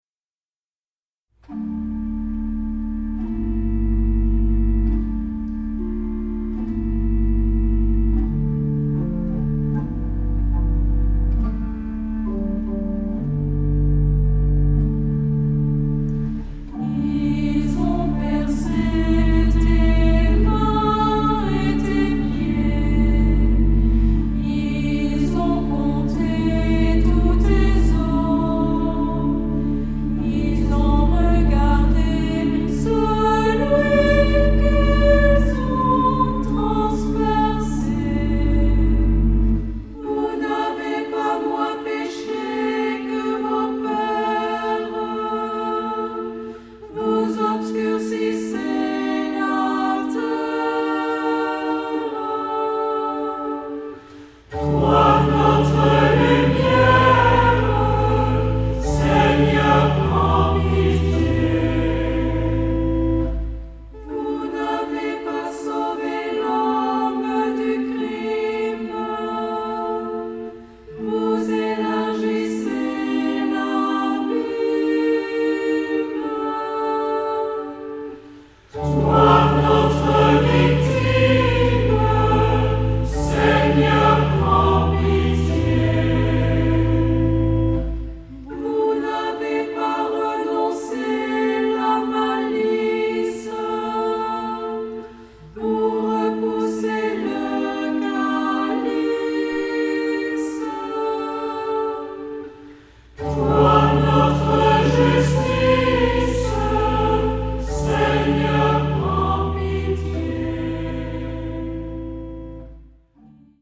Genre-Style-Form: troparium
Mood of the piece: collected ; slow
Type of Choir: SATB  (4 mixed voices )
Instrumentation: Organ (optional)  (1 instrumental part(s))
Tonality: D minor